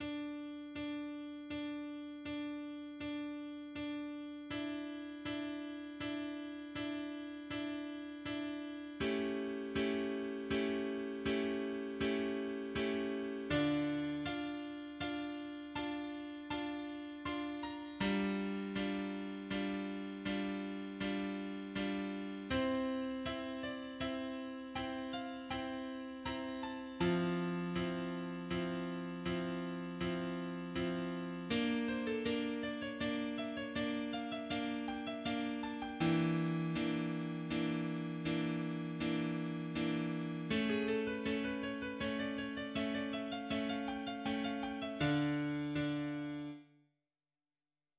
– and in Bach's keyboard arrangement of Alessandro Marcello's Concerto for Oboe and Strings.
Bach adagio BWV 974 (after Marcello)